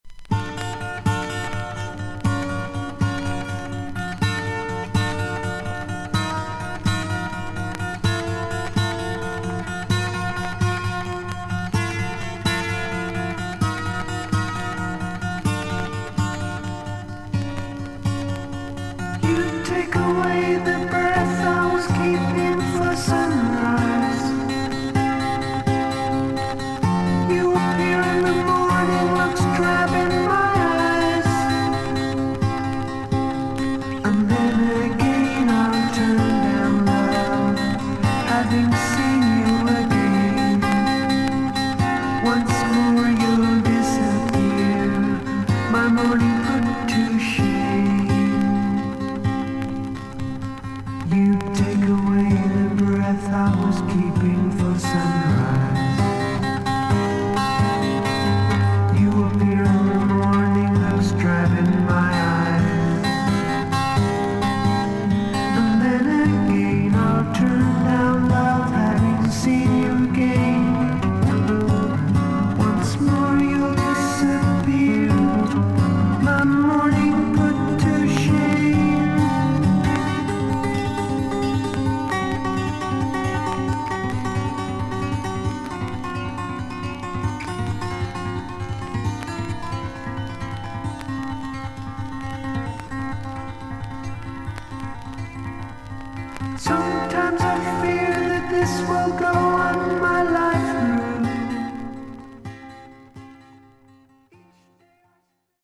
それほどノイズは出ませんが、B4前半少々周回ノイズあり。
少々サーフィス・ノイズあり。クリアな音です。